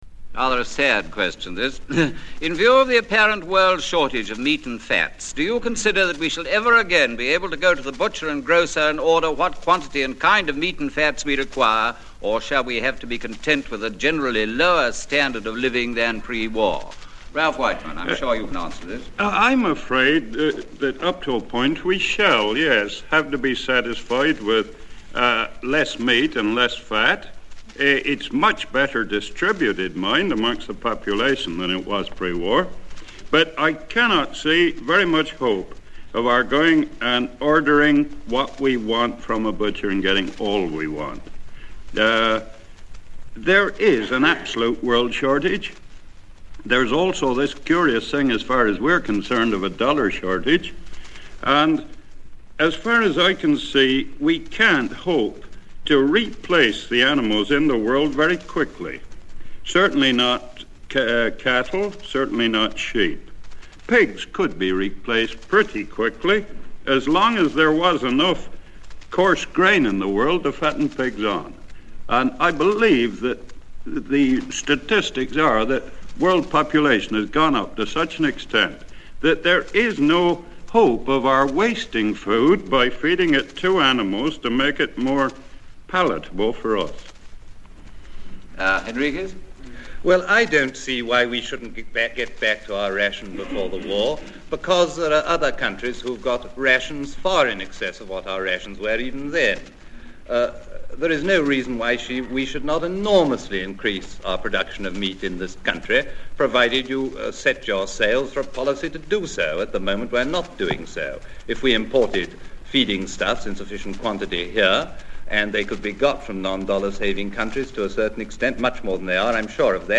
As a fan of programmes away from the 'artificial atmosphere' of the studio, this weekly political panel show outside broadcast was a natural idea. The first programme came from Winchester in 1948, hosted by Freddie Grisewood.